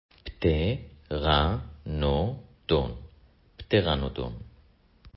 פט-ר-נו-דון